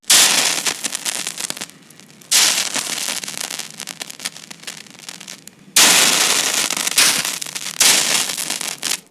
Agua cayendo sobre una plancha caliente
vapor
Sonidos: Agua
Sonidos: Hogar